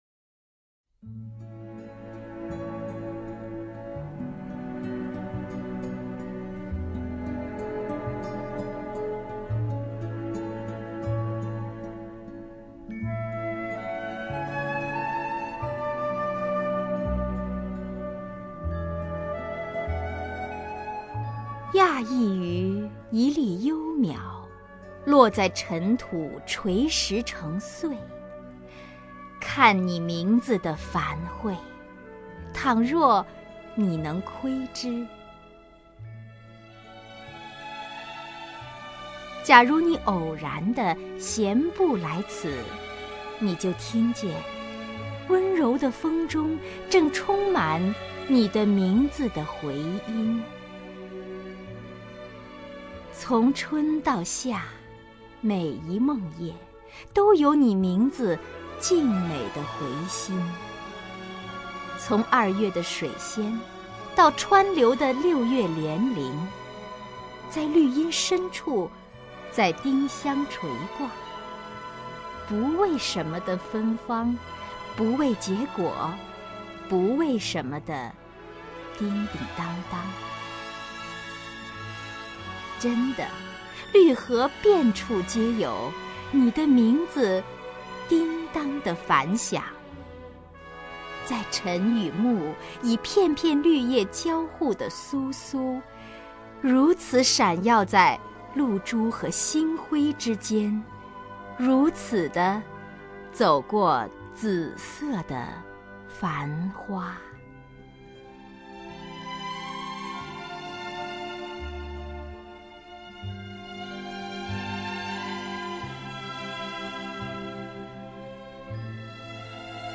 首页 视听 名家朗诵欣赏 王雪纯
王雪纯朗诵：《看你名字的繁卉》(蓉子)